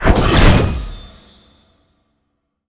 spell_harness_magic_03.wav